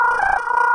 描述：从阴森恐怖的FX包。
Tag: 怪异 女巫 实验 令人毛骨悚然 编辑 吓人 声音 闹鬼 女性 效果 FX 噪音 干燥 阴森恐怖 沉默 爱迪生 蓝色 音调 翻录 困扰